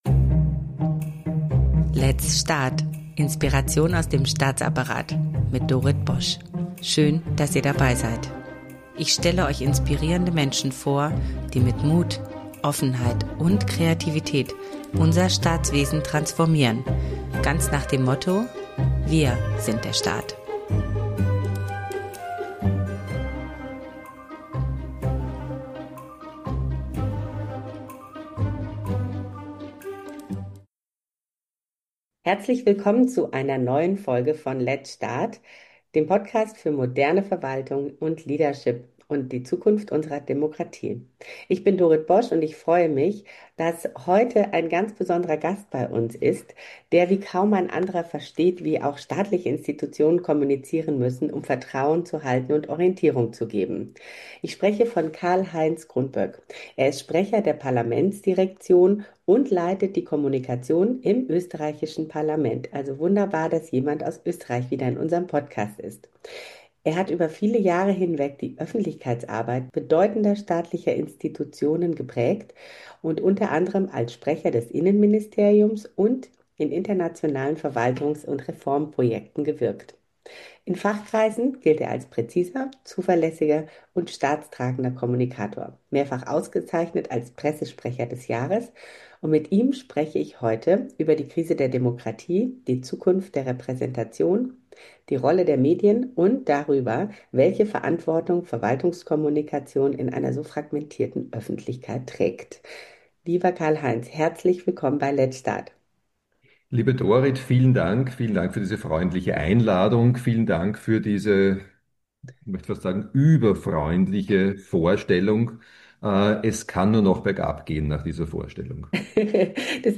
Dieses Gespräch ist eine Erinnerung daran, warum klare Rollen zwischen Behörden und Politik in der Bevölkerung Vertrauen schaffen.